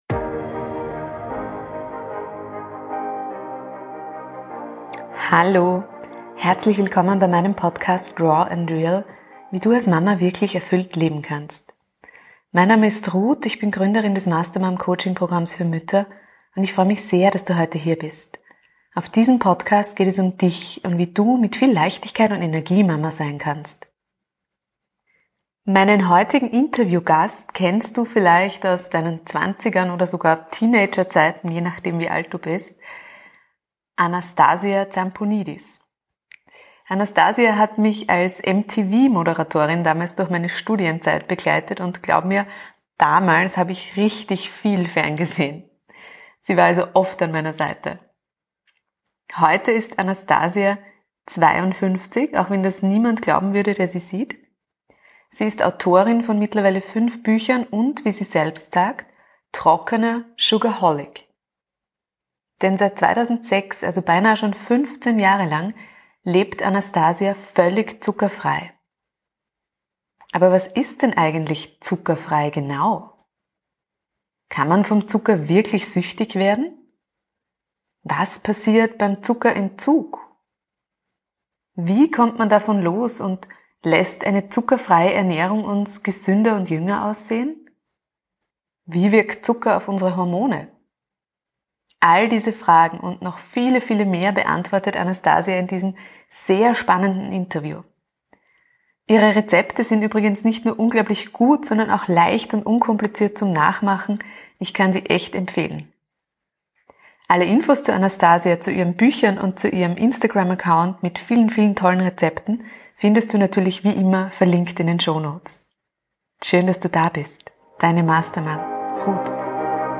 #90 Ein Leben ohne Zucker. Interview mit Bestsellerautorin Anastasia Zampounidis ~ raw and real Podcast